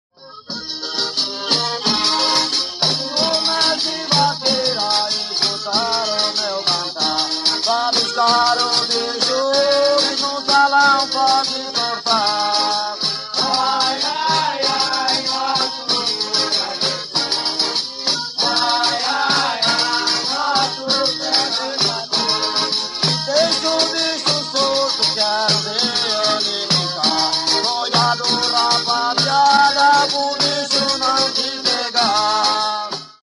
Boi-de-mamão
Folguedo do boi que ocorre em Santa Catarina, composto de quadros rápidos entrecortados pelas canções de repentistas.
boidemamao.mp3